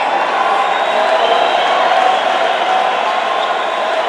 crowd.wav